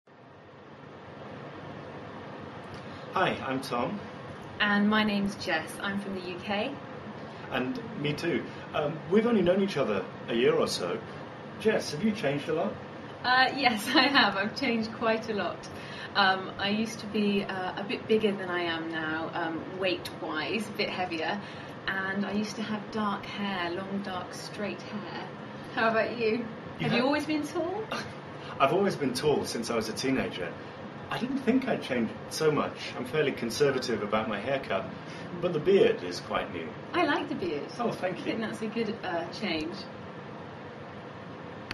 实战口语情景对话 第1200期:Have you changed a lot? 你变了很多吗?